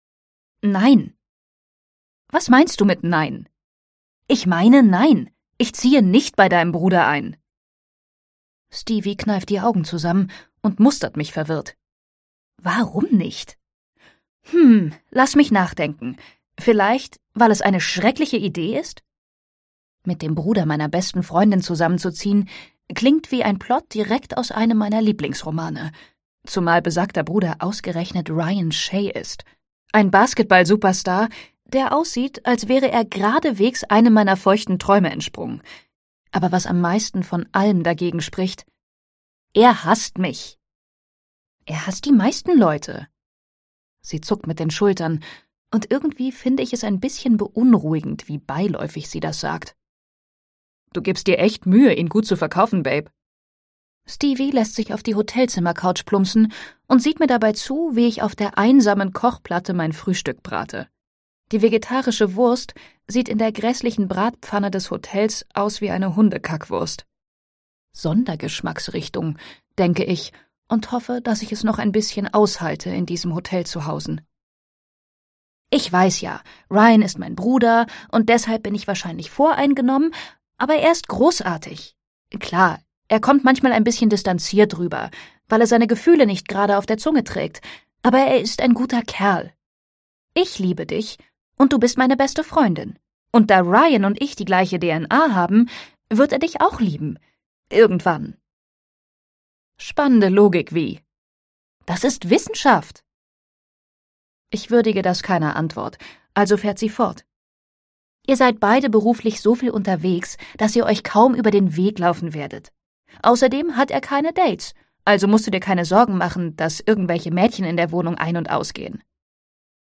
Entdecken Sie "The Right Move" von Liz Tomforde als Hörbuch Download bei Penguin.